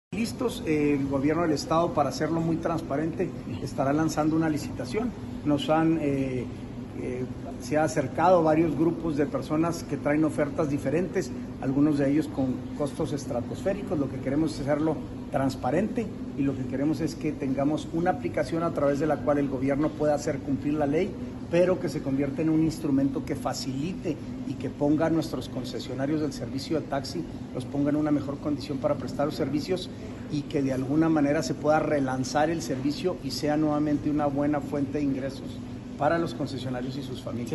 AUDIO: SANTIAGO DE LA PEÑA, SECRETARÍA GENERAL DE GOBIERNO (SGG)